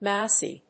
音節mous・y 発音記号・読み方
/mάʊsi(米国英語), ˈmaʊsi:(英国英語)/